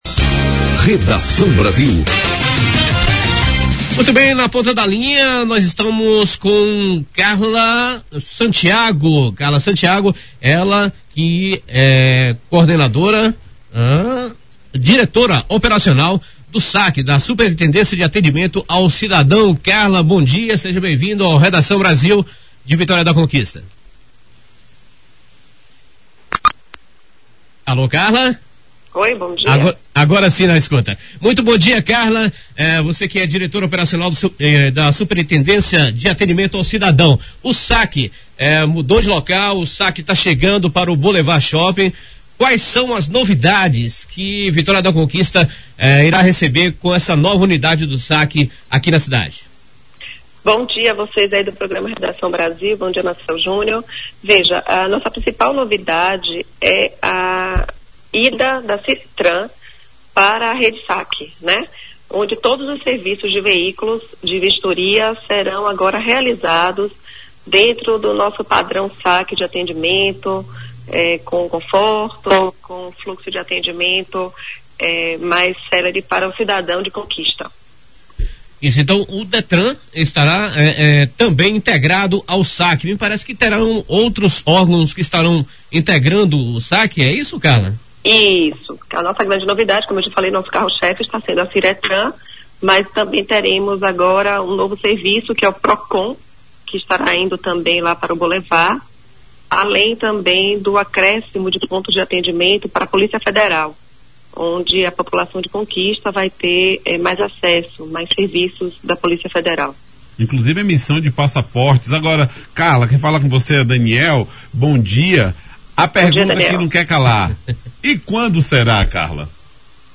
Reportagem do Redação Brasil: SAC está pronto, mas ainda sem data para inauguração em Vitória da Conquista